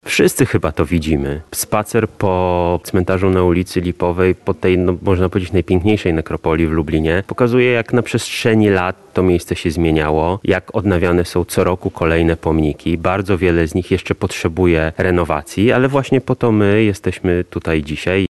Wszyscy chyba to widzimy – mówi poseł Michał Krawczyk, który dziś kwestował przy Lipowej.